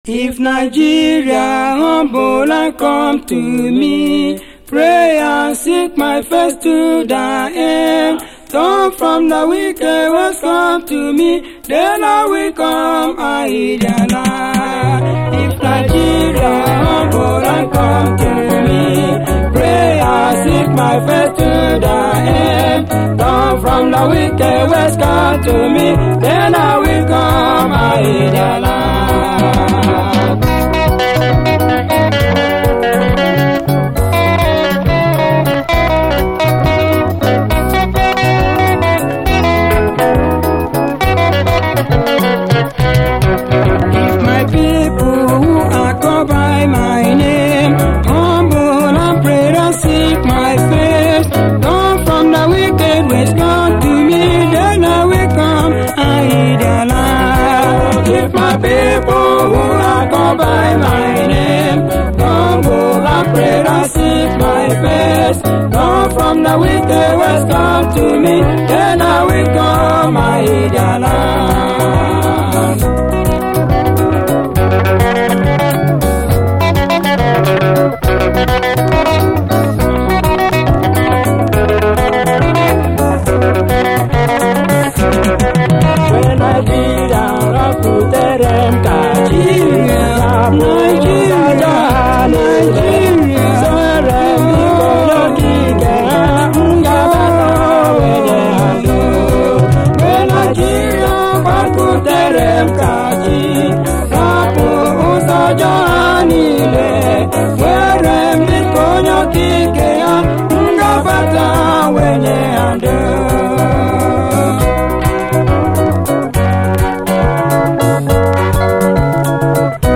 AFRO, WORLD
80'S ナイジェリア産ゴスペル・ミュージック！アフロ特有の6/8拍子の粘っこいリズムで優しい音色と歌声が沁みます。